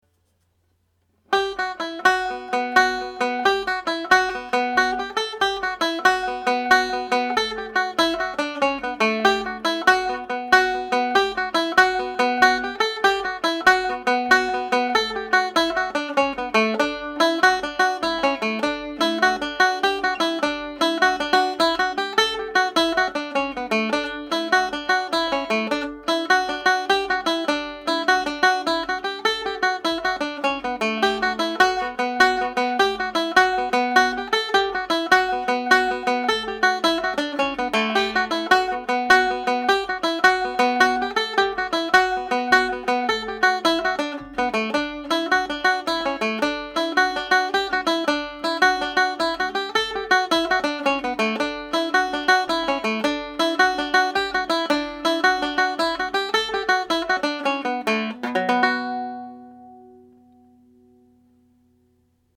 This is the second tune of the set of two slip jigs paired with Cathal McConnell’s. The highlighted F# can be played or left out.
The Humours of Whiskey played at normal speed